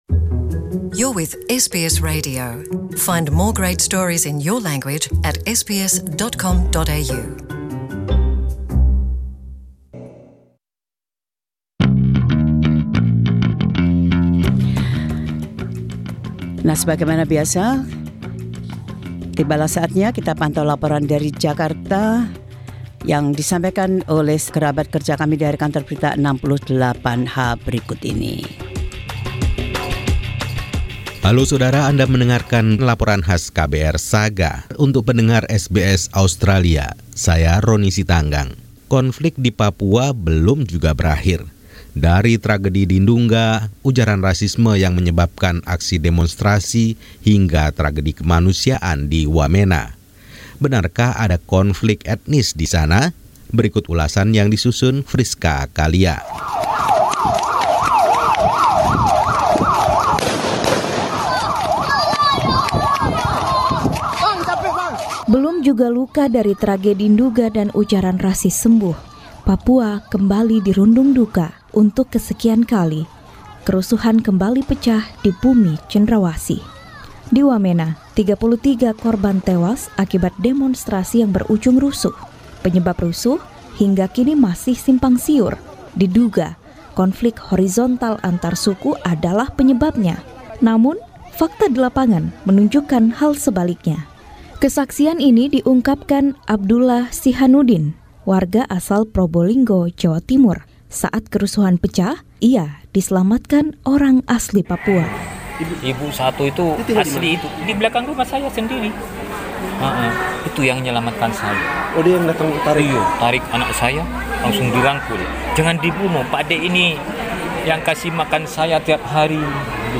Tim KBR 68H melaporkan beberapa peristiwa yang terjadi selama dan setelah kerusuhan pada tanggal 23 September.